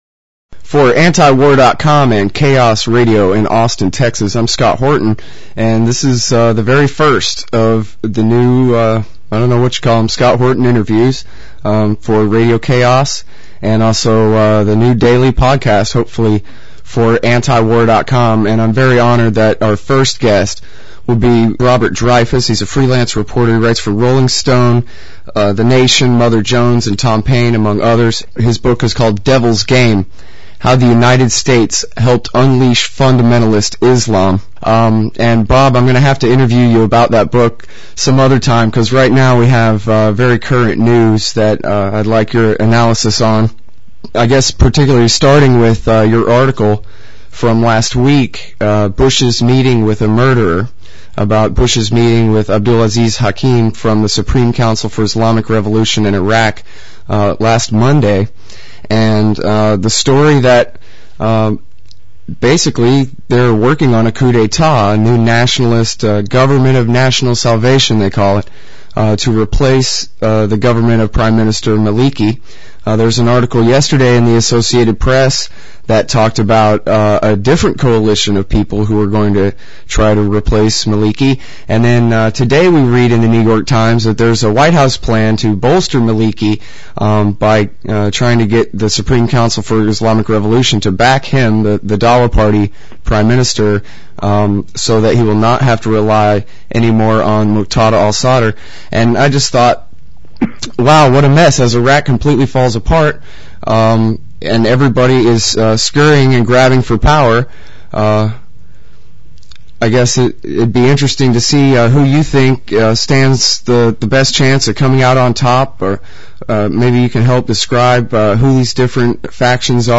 Interviews
(31:58) My sound guy (me) didn’t do the best job and my interviewing skills are a little rusty, but these are problems that can soon be resolved.